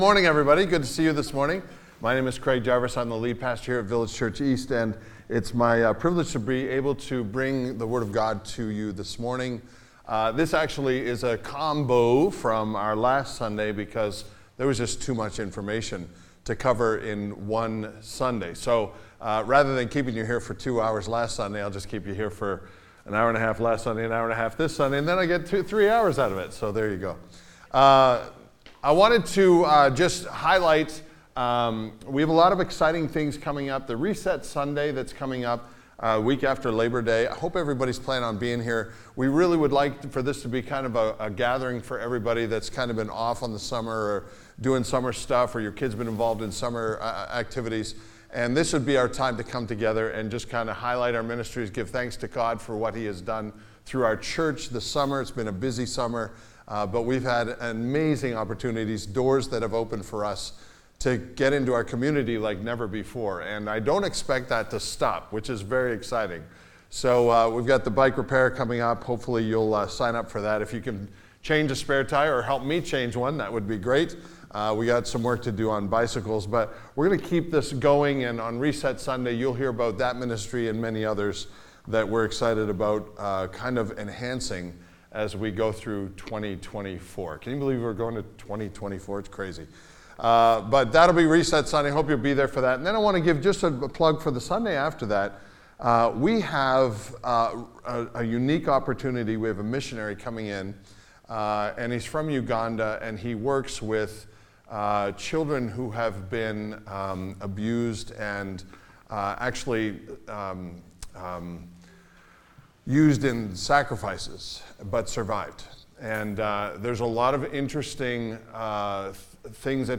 This sermon is a part 2 as it dives deeper into how to aproach spirits and demonic influence and discern them with a Godly perspective.&nbsp